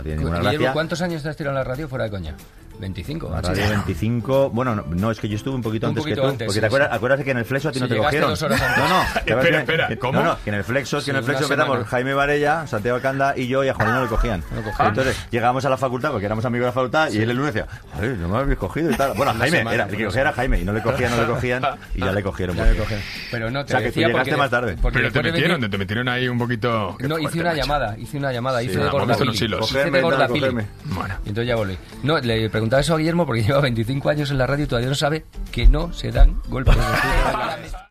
Entrevista a Guillermo Fesser i José Luis Cano sobre l'antecededent de Gomaespuma al programa "El flexo" de Radio Madrid
Entreteniment